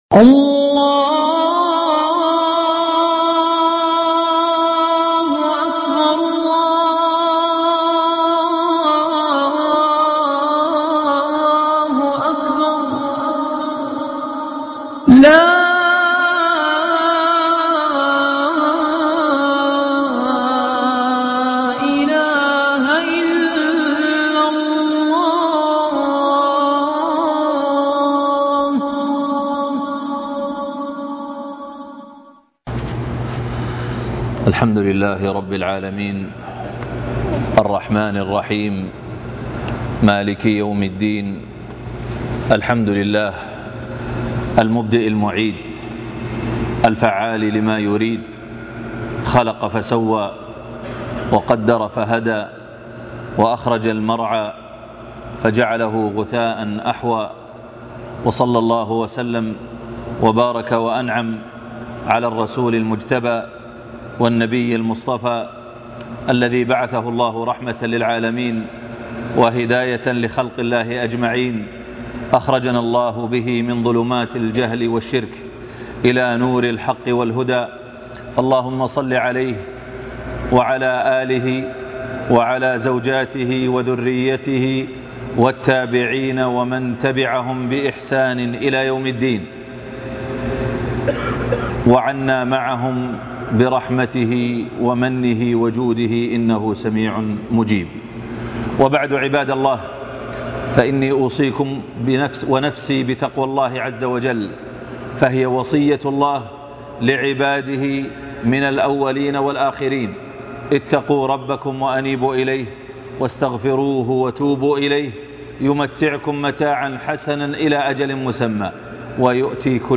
سورة الإخلاص صفة الرحمن (خطب الجمعة